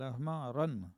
Langue Maraîchin
locutions vernaculaires
Catégorie Locution